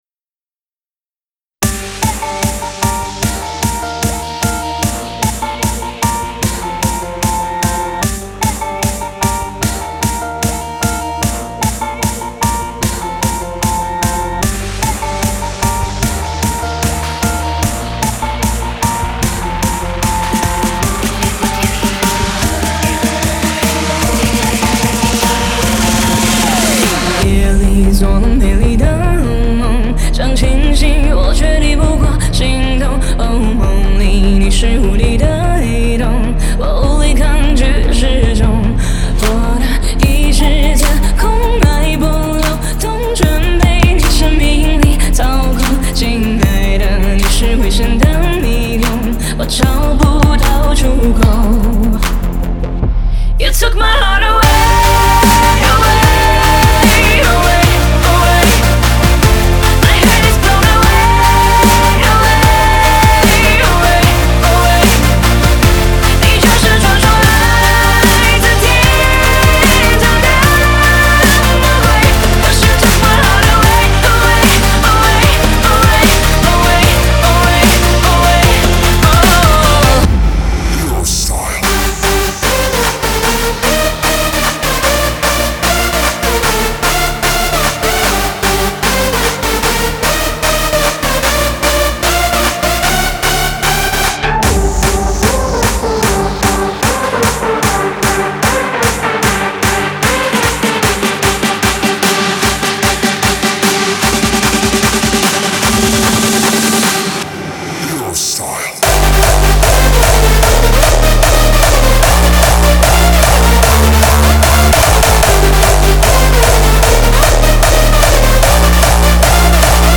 5天前 DJ音乐工程 · Hardstyie风格 2 推广